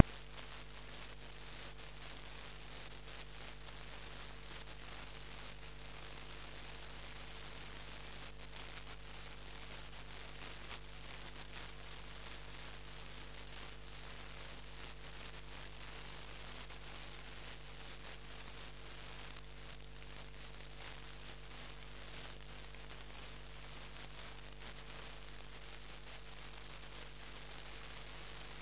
принята на частоте 1659 кгц. начало передачи в 23:03 UTC
интересно что в записях одинаковый голос.